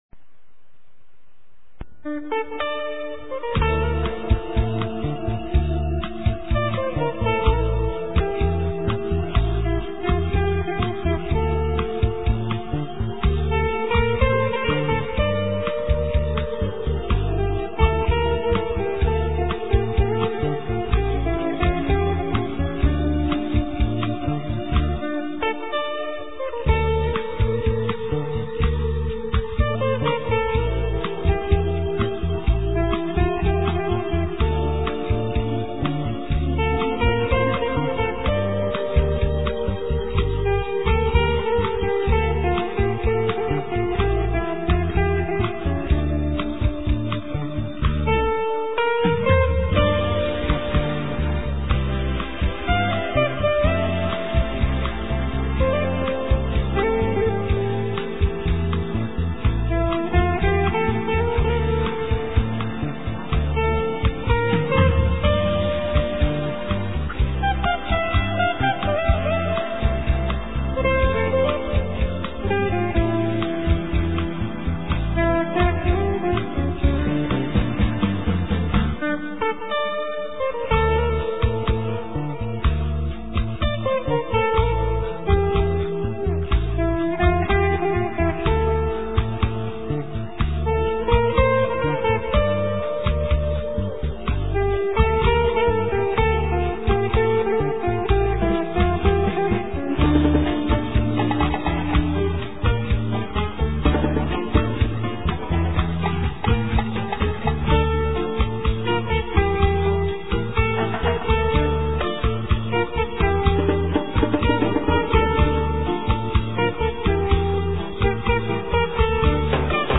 * Ca sĩ: Không lời